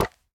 resin_brick_hit2.ogg